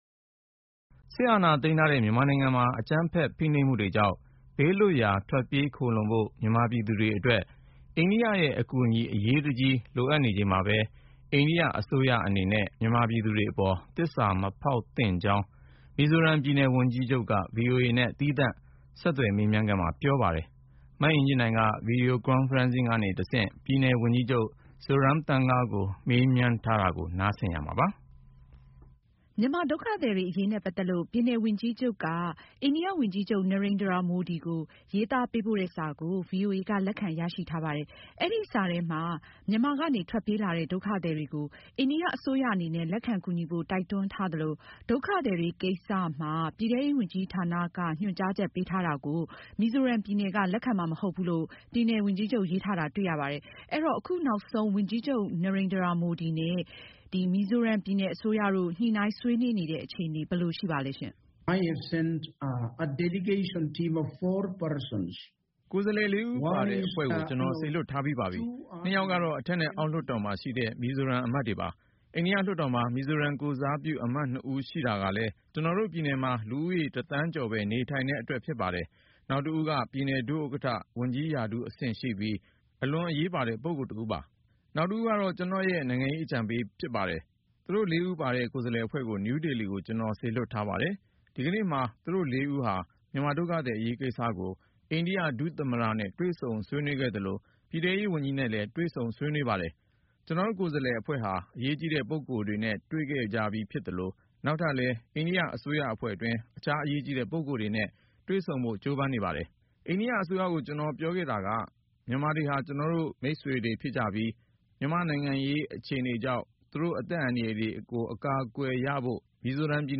တိမ်းရှောင်လာတဲ့ မြန်မာတွေကို လက်ခံရေး မီဇိုရမ် ဝန်ကြီးချုပ်နဲ့ ဆက်သွယ်မေးမြန်းချက်